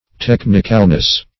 Search Result for " technicalness" : The Collaborative International Dictionary of English v.0.48: Technicalness \Tech"nic*al*ness\, n. The quality or state of being technical; technicality.